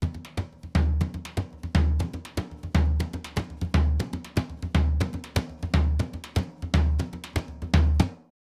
Surdo Baion 120_1.wav